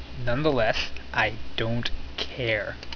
hey this is my new page. if you're here, most likely you already know me and you're not going to learn anything anyway. nonetheless, i don't care. you can hear how i annunciate "nonetheless, i don't care"